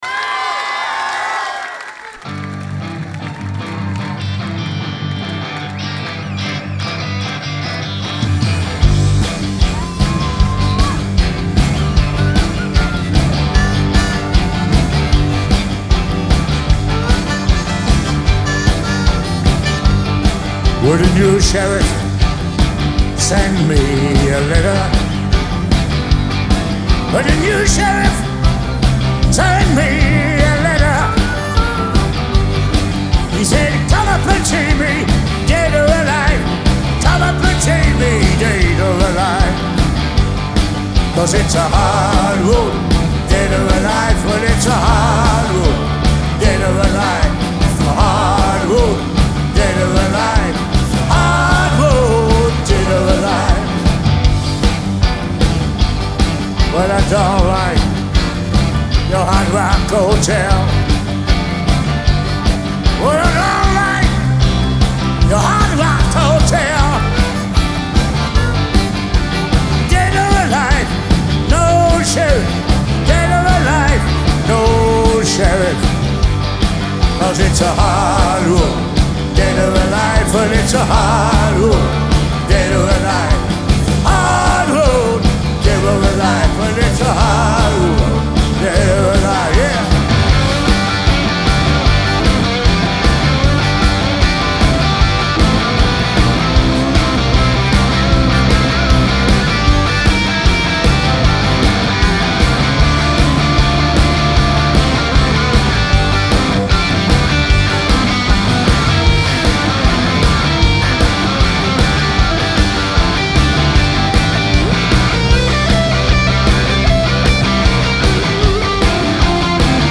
Genre : French Music